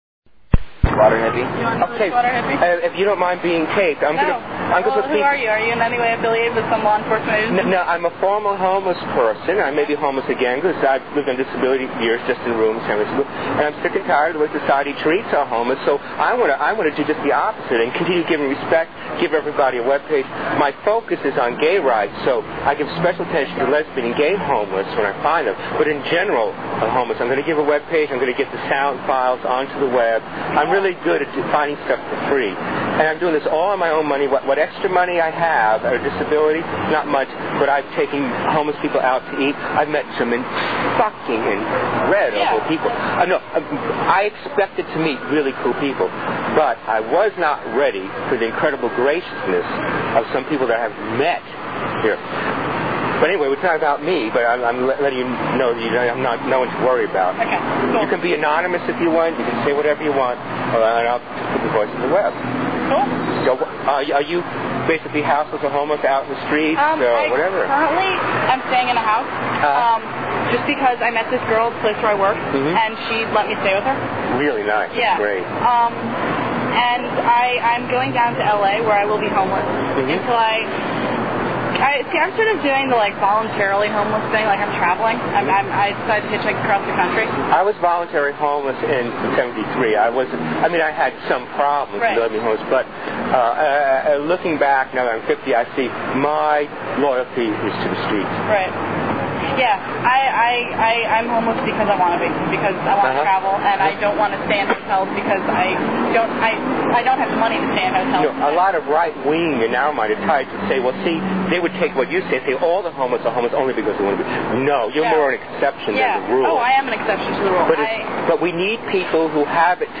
Audio Interviews - Street Folk